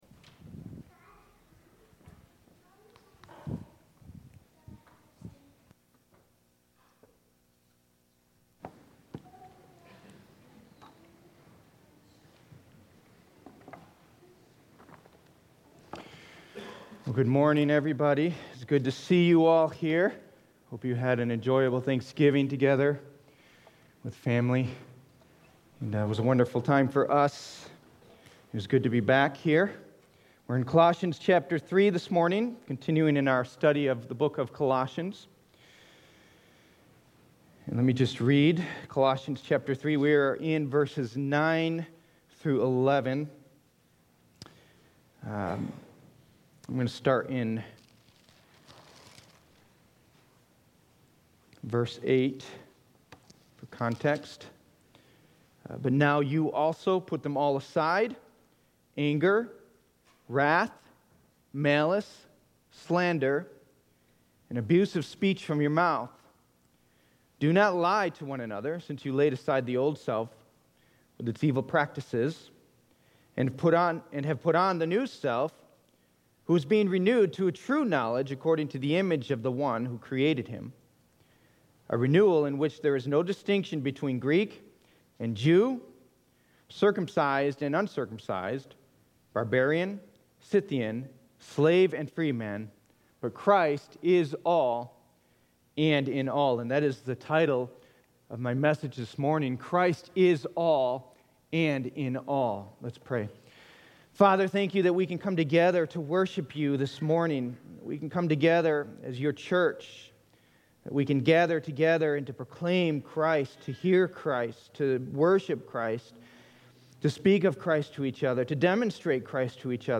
Faith Baptist Church archived sermons